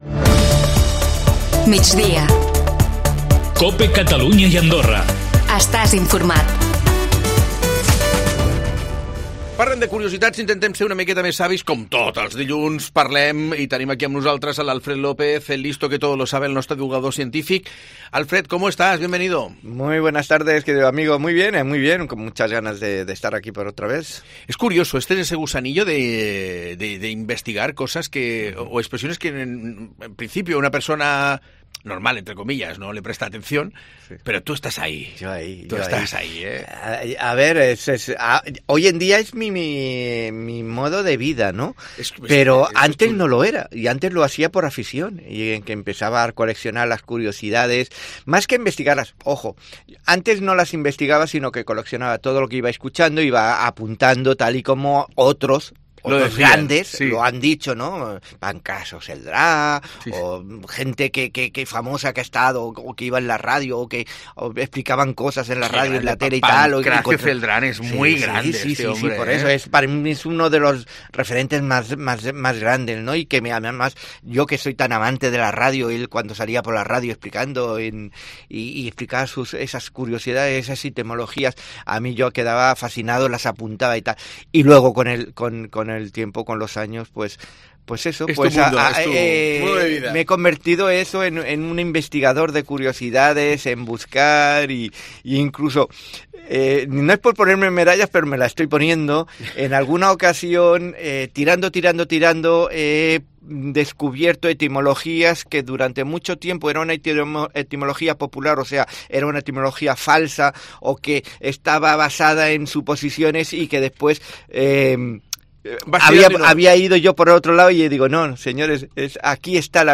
Aquestes són les curiositats escollides per avui: (ESCOLTA L'ENTREVISTA AQUÍ) -D'on sorgeix dir que algú benvolent té ‘màniga ampla’?